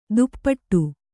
♪ duppaṭṭu